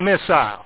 w1_missile.mp3